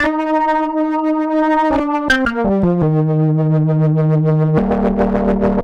AC_OrganA_85-C.wav